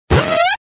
contributions)Televersement cris 4G.